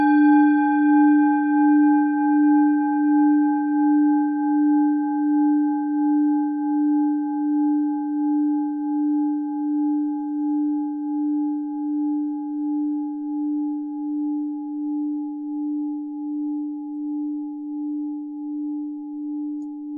Klangschalen-Typ: Bengalen
Klangschale Nr.6
Gewicht = 590g
Durchmesser = 14,5cm
(Aufgenommen mit dem Filzklöppel/Gummischlegel)
klangschale-set-1-6.wav